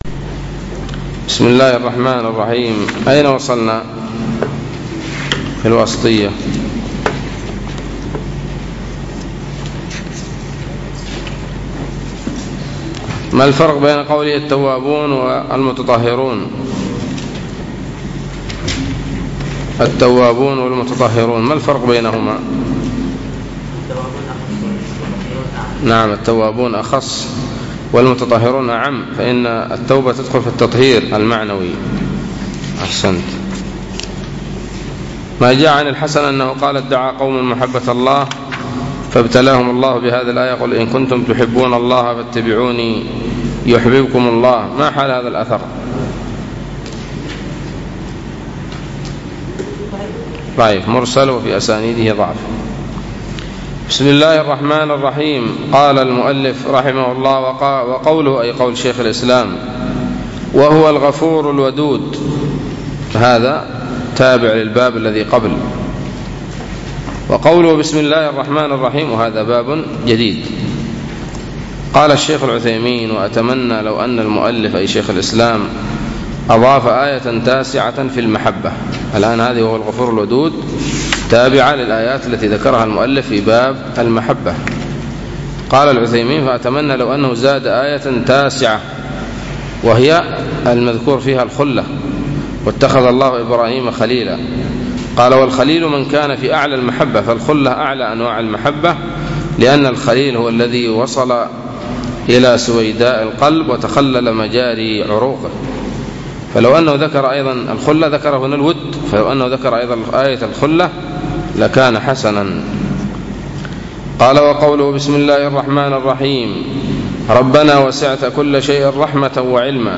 الدرس الواحد والخمسون من شرح العقيدة الواسطية